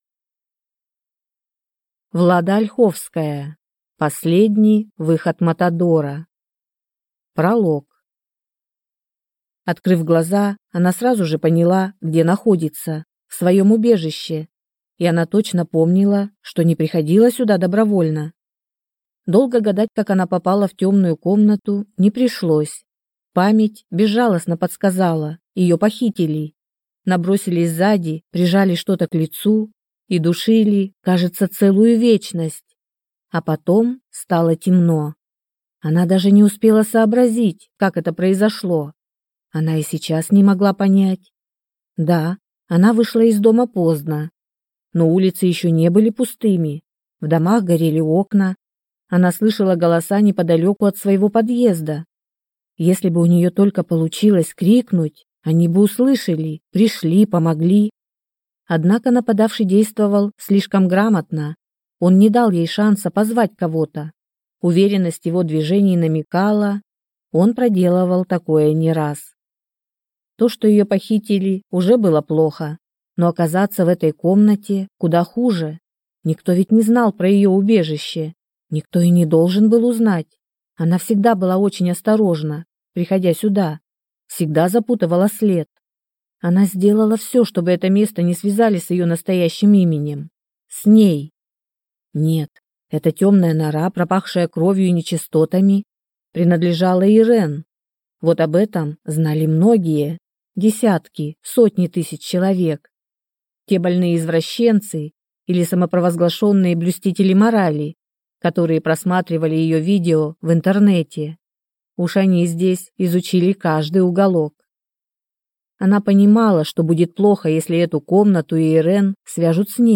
Аудиокнига Последний выход Матадора | Библиотека аудиокниг